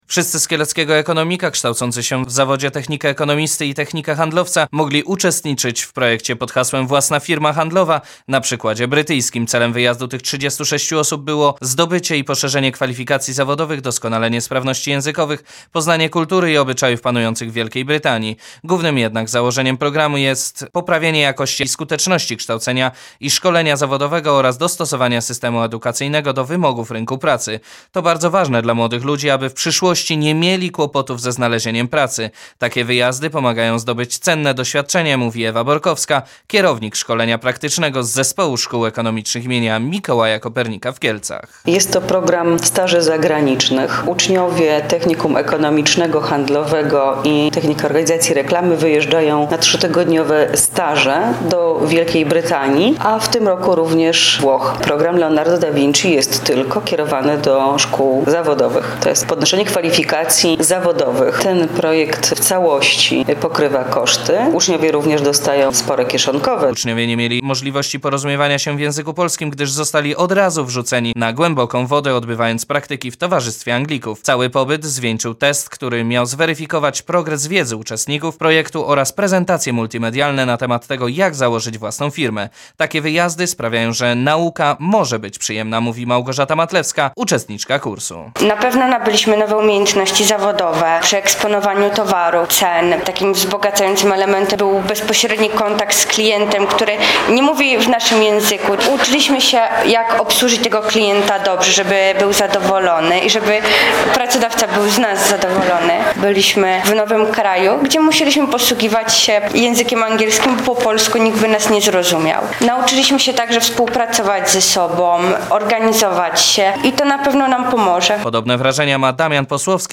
Wywiad w Radiu PLUS z uczestnikami stażu
wywiad.mp3